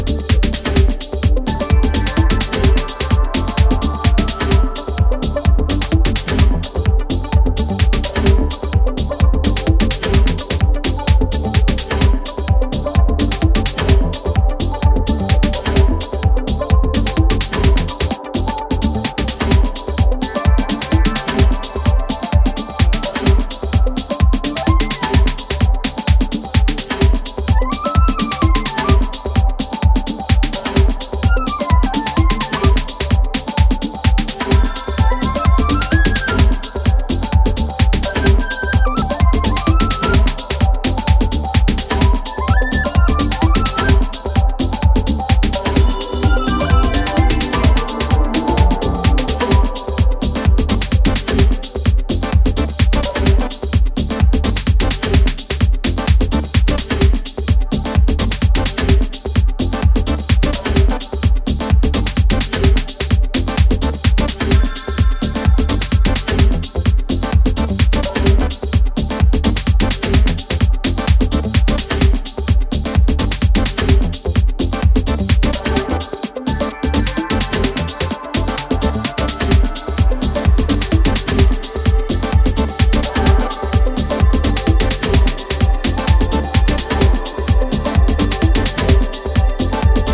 淡くきめ細やかな要素が乱反射する